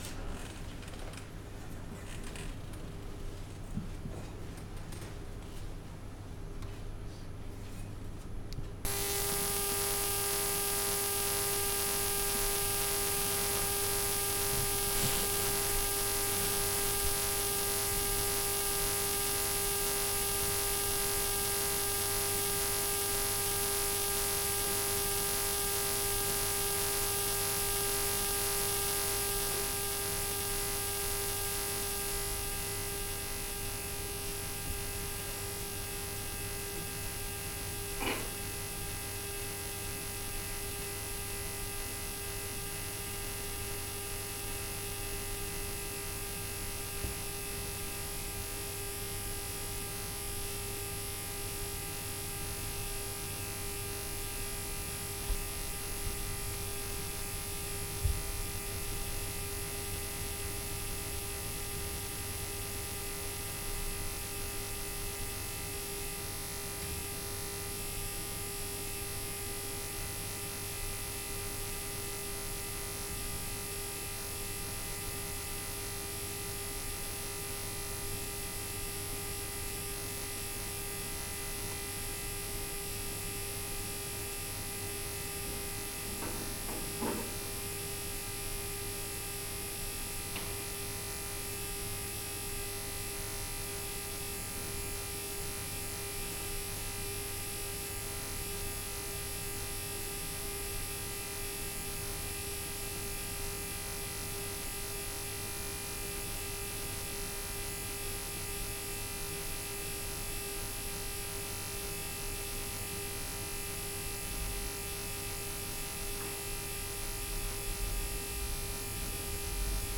The Arches, Glasgow
It simply says that the performance should last 65 mins (two 30min halves with a five minute silent interlude). Musicians can chose any sound to make, but must make it for between one and 15 mins in each half. The length of the sound should be inversely proportional to its volume: short = comfortable, long = very quiet.